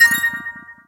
success_kick.wav